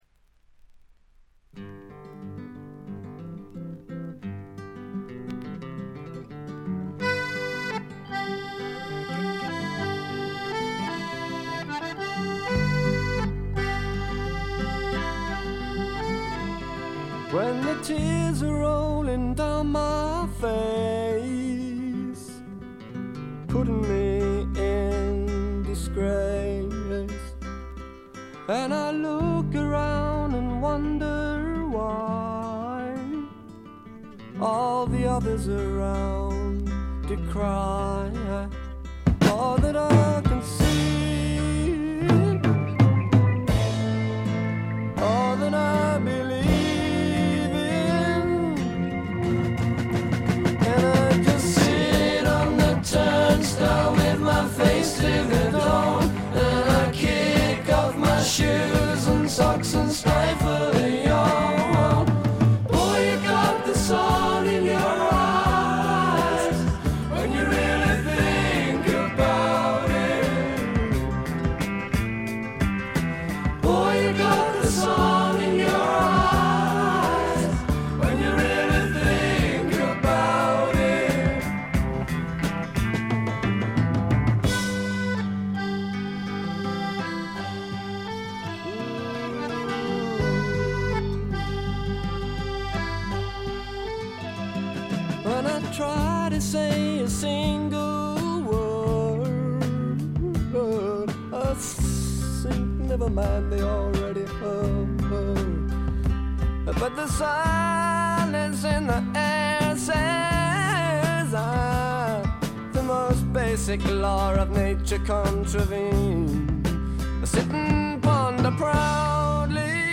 静音部で軽微なチリプチ少々。散発的なプツ音3箇所ほど。
いわずと知れた英国フォークロックの名盤です。
アメリカでは絶対に生まれ得ない、陰り、くすみ、ほのかなプログレ風味といった陰影に富んだ英国臭がふんだんに味わえます。
哀愁の英国フォークロック基本盤。
試聴曲は現品からの取り込み音源です。
Recorded at Olympic Sound Studios and Morgan, April 1971.